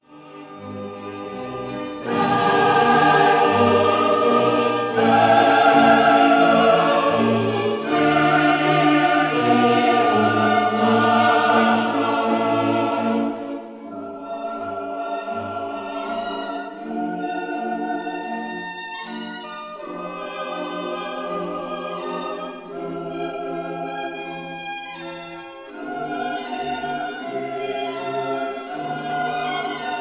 soprano
contralto
tenor
bass